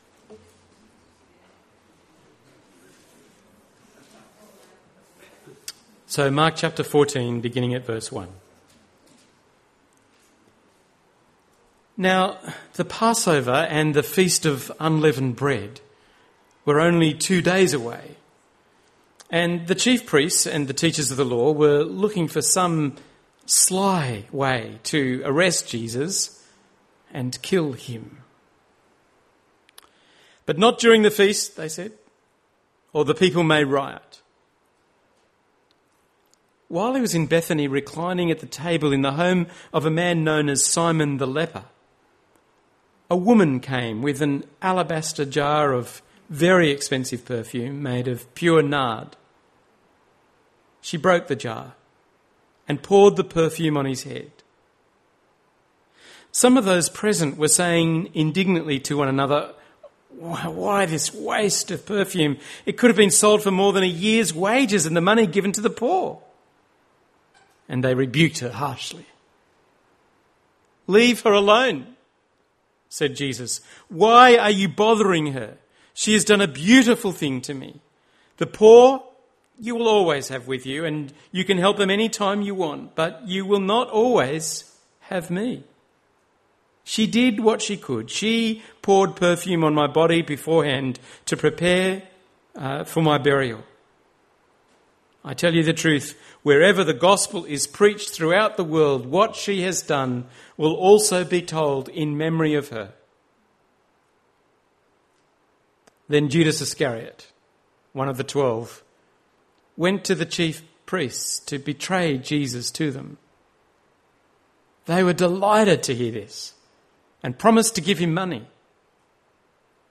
The third talk in our lenten series for 2017.